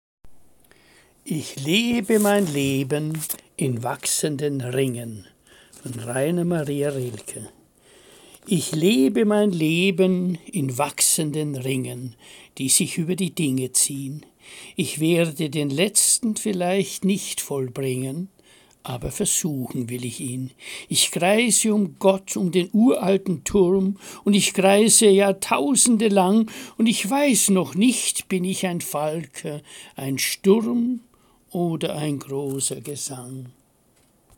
Lesung - Musikvideo